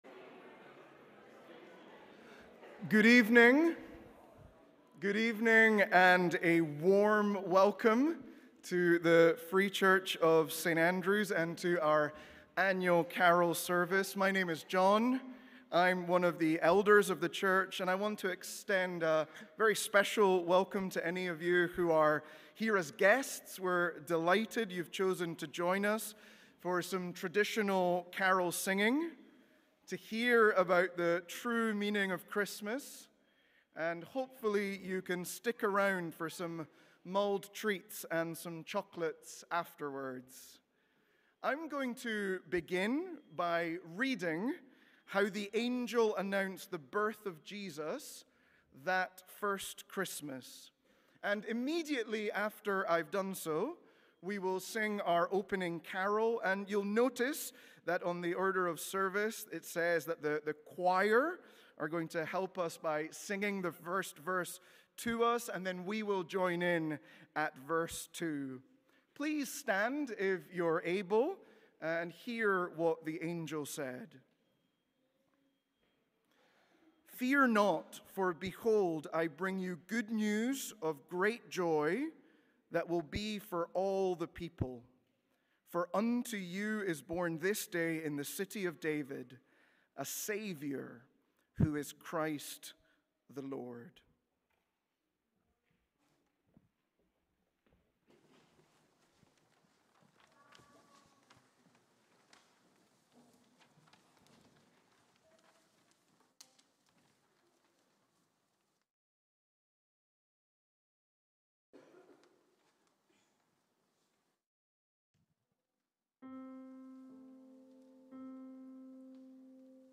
Traditional Carol Service 2024